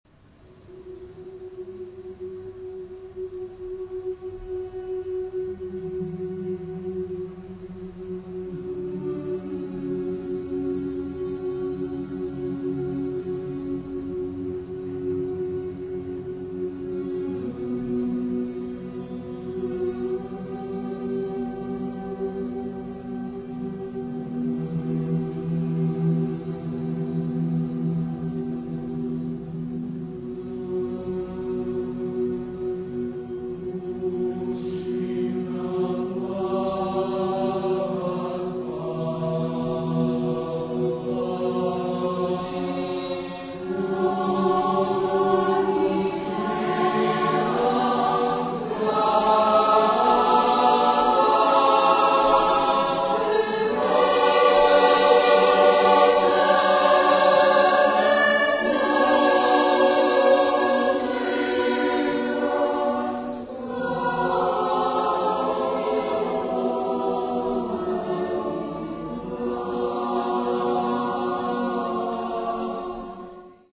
Genre-Style-Forme : Profane ; Lied
Caractère de la pièce : dramatique
Type de choeur : SATB  (4 voix mixtes )
Tonalité : fa dièse mode de mi
Consultable sous : 20ème Profane Acappella